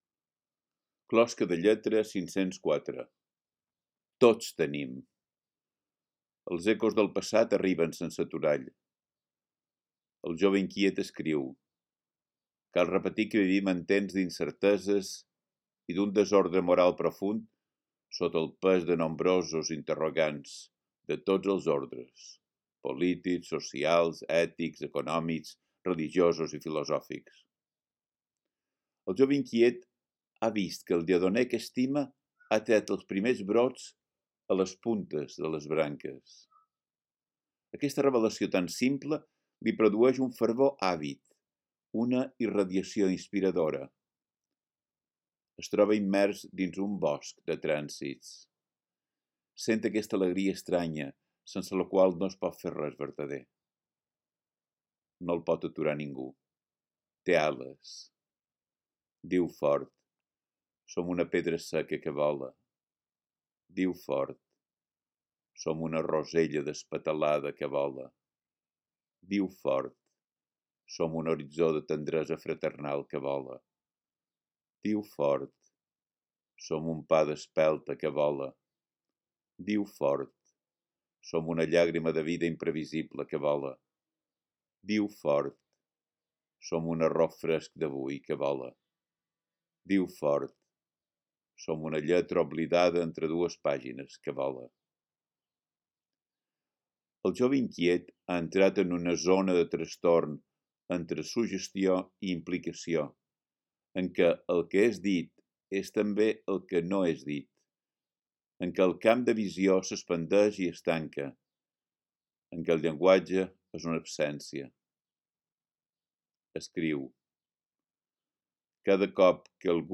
Podeu escoltar el text recitat per Biel Mesquida mateix: